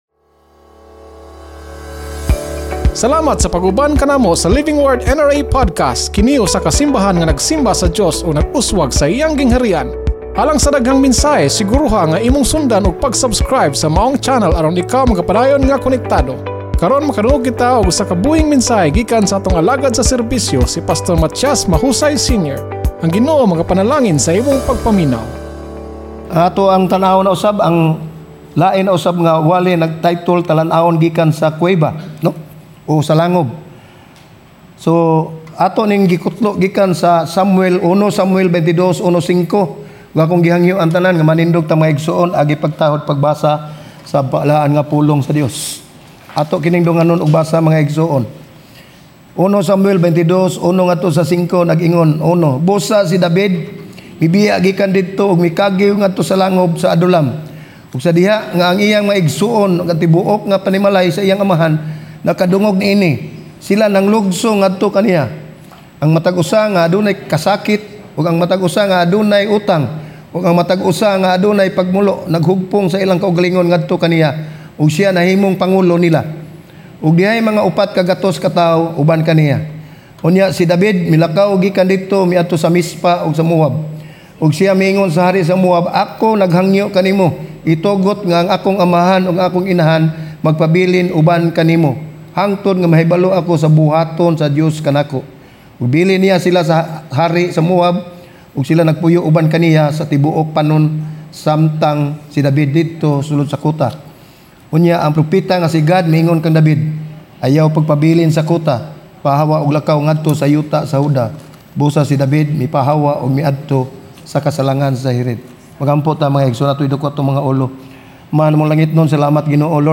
Ang paglangan sa Dios dili kinahanglan nagpasabot nga naglimod ang Dios. Sermon